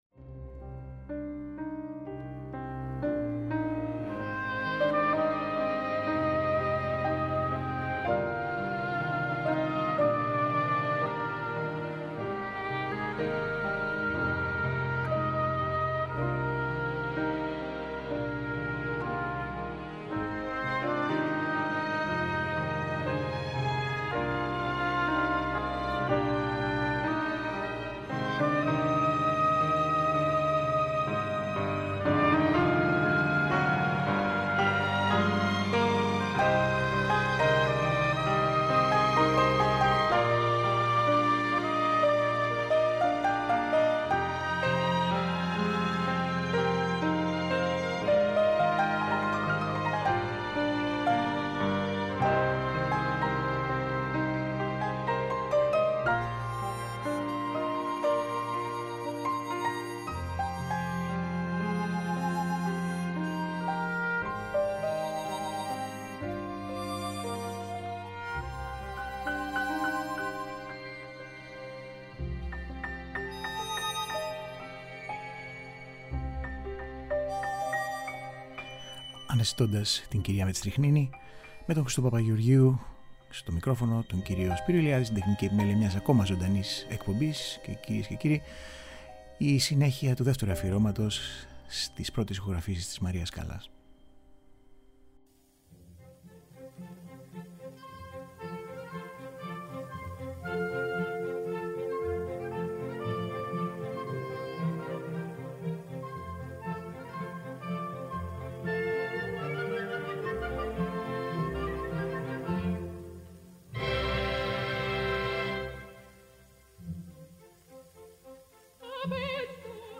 Μία σειρά πέντε ωριαίων εκπομπών με ηχογραφήσεις και αφηγήσεις όπως αυτές αποτυπώνονται μέσα από την πλούσια βιβλιογραφία για την μεγάλη αυτή προσωπικότητα της κοινωνικής ζωής και της τέχνης του 20ου αιώνα.
Την Τρίτη 23 Μαίου θα παρουσιαστεί η χρυσή δεκαετία της ανεπανάληπτης πορείας της μέσα από χαρακτηριστικές ηχογραφήσεις, συνεντεύξεις και μαρτυρίες και παράλληλα το προσωπικό της δράμα έως την κορύφωση του.